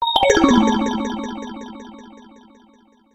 鉄琴の一種のヴィブラフォン音色。
メール音やSMSの通知音。